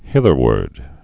(hĭthər-wərd) also hith·er·wards (-wərdz)